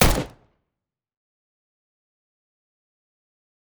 Assault Rifle Shot 4.wav